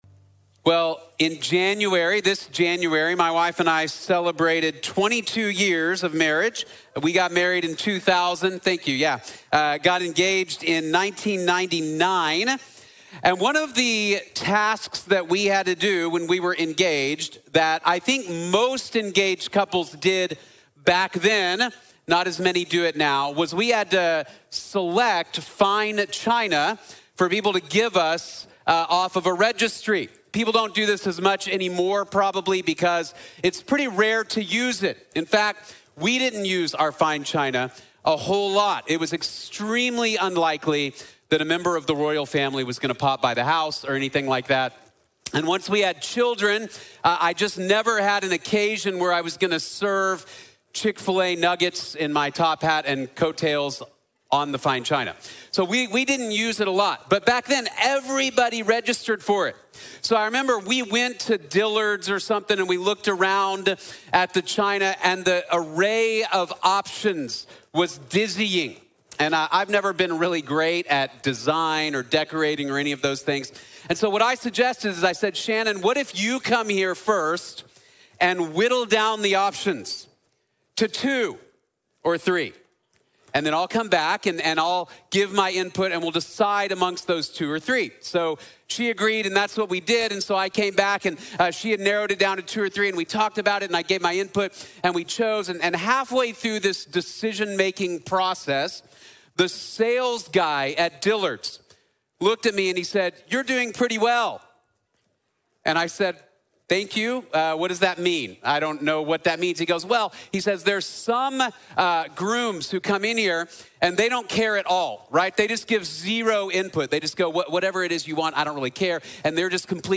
In Christ Alone | Sermon | Grace Bible Church